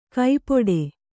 ♪ kaipoḍe